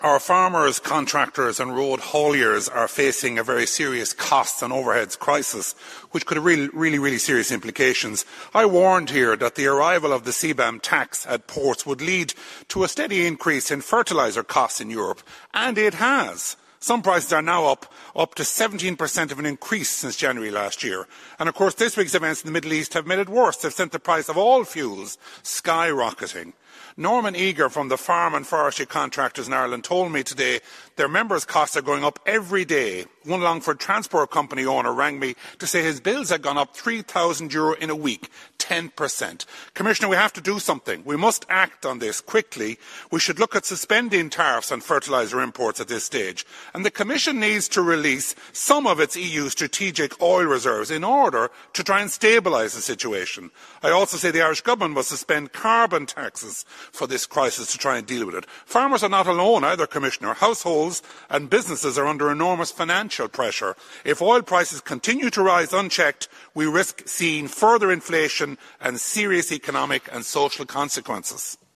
Speaking in Strasbourg, Ciaran Mullooly said the EU’s Carbon Border Adjustment Mechanism is increasing fertiliser costs for farmers, and now, rising oil prices are compounding the situation.